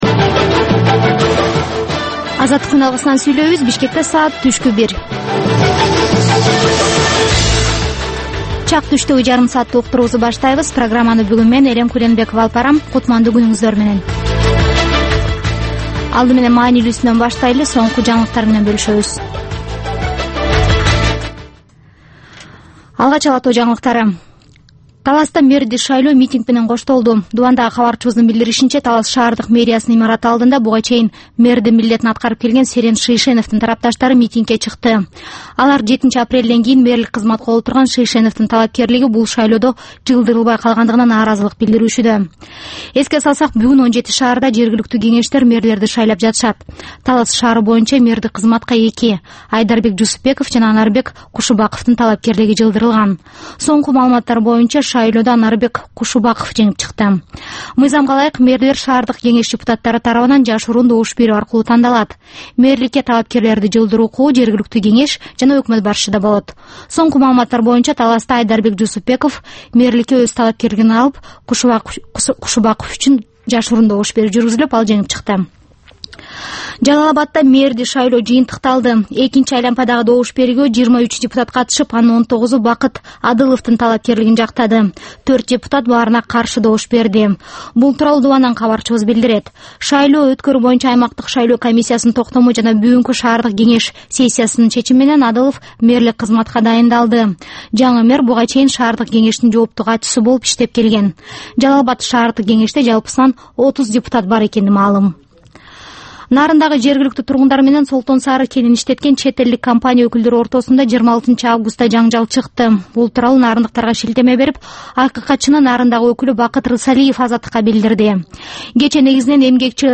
Түшкү саат 1деги кабарлар